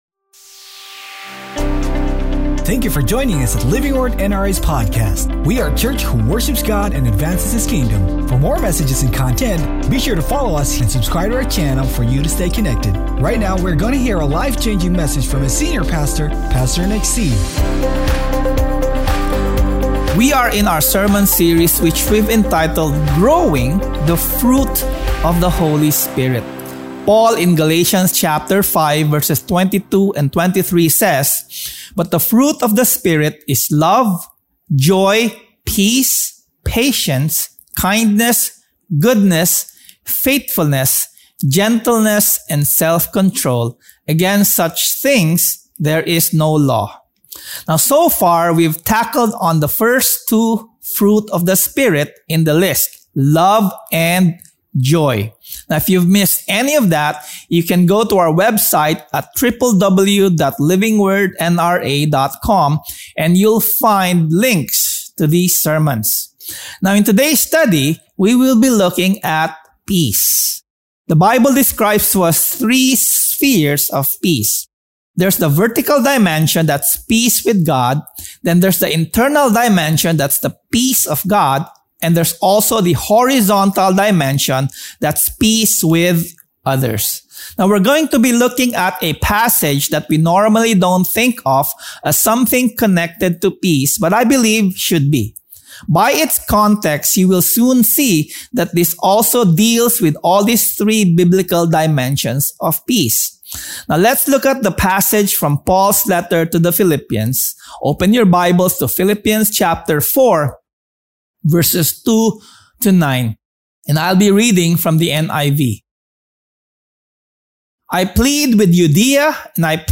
Sermon Title: PRESCRIPTIONS FOR PEACE Scripture Text: GALATIANS 5:22-23 ; PHILIPPIANS 4:2-9